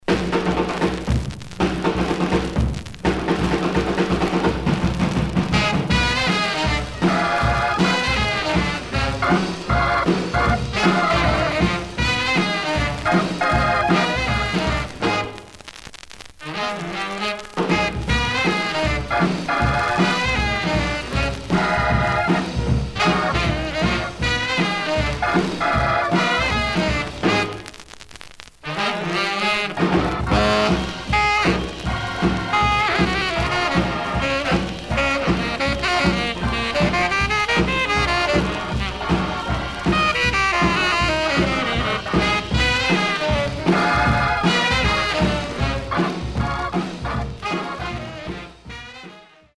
Some surface noise/wear
Mono
R&B Instrumental